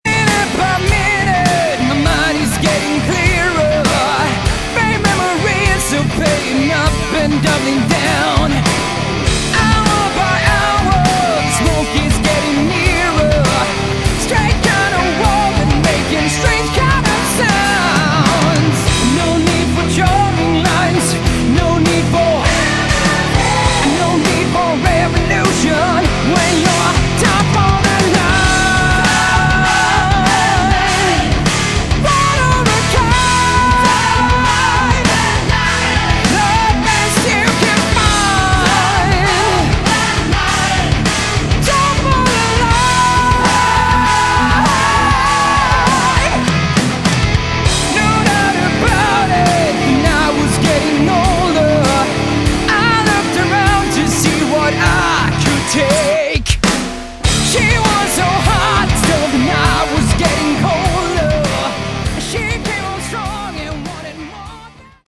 Category: Hard Rock
Vocals
Guitars
Drums
Bass